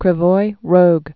(krĭ-voi rōg, rôk)